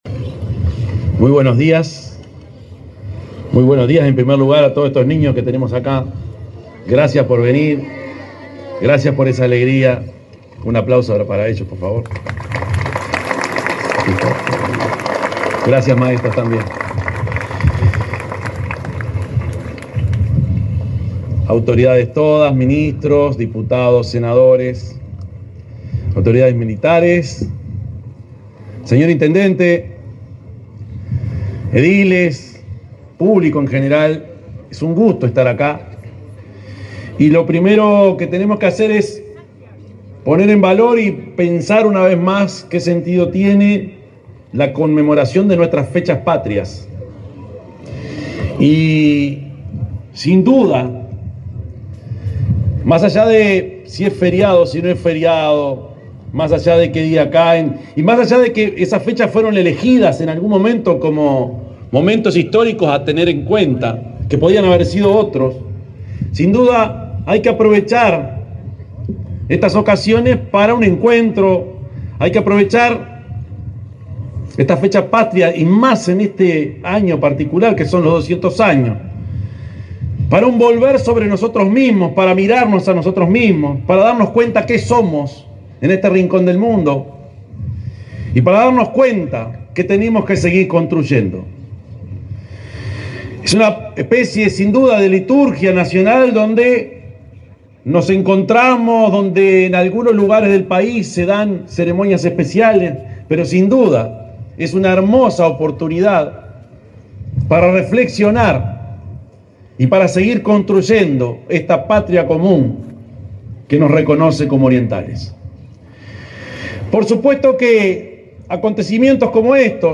Palabras del presidente de la República, profesor Yamandú Orsi
Palabras del presidente de la República, profesor Yamandú Orsi 19/04/2025 Compartir Facebook X Copiar enlace WhatsApp LinkedIn Este sábado 19 de abril, en la playa de la Agraciada, en el departamento de Soriano, el presidente de la República, Yamandú Orsi, participó del acto conmemorativo del bicentenario del Desembarco de los Treinta y Tres Orientales.